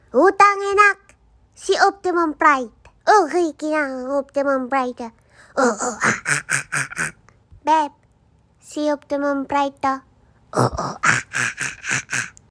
Si Optimum Pride sound skill sound effects free download